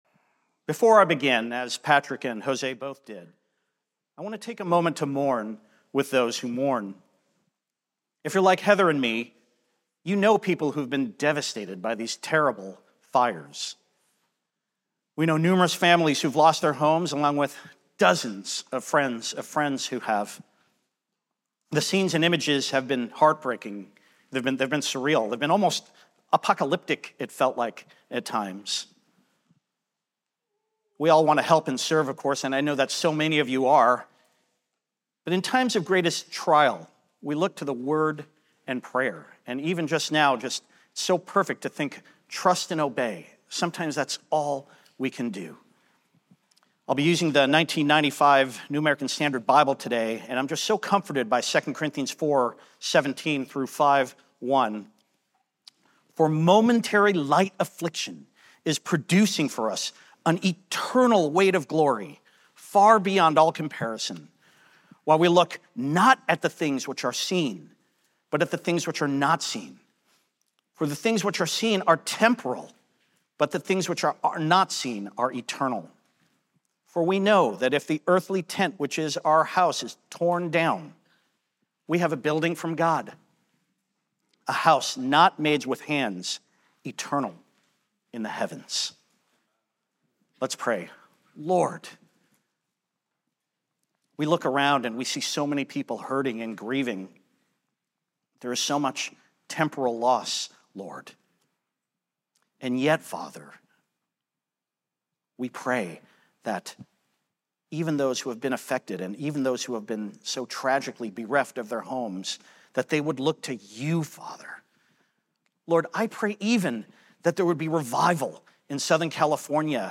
occasional guest speakers deliver God's Word at Christ Bible Church's weekly Sunday services.